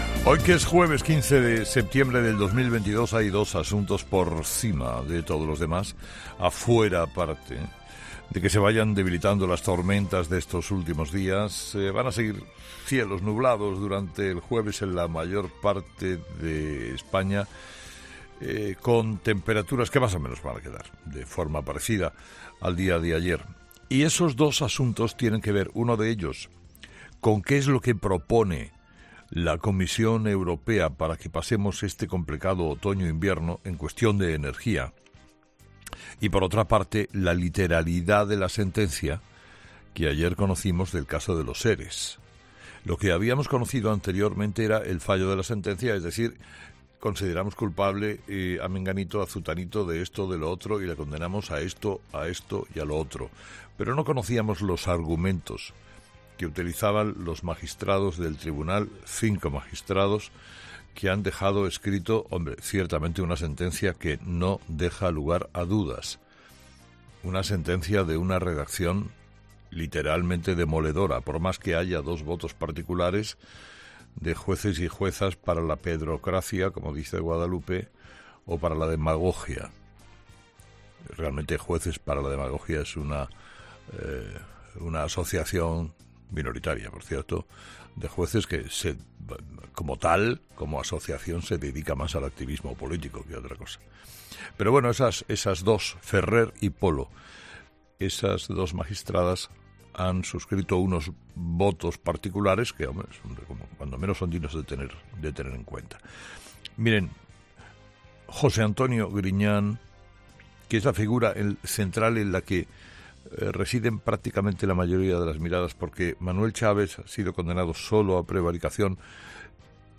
Carlos Herrera repasa los principales titulares que marcarán la actualidad de este jueves 15 de septiembre en nuestro país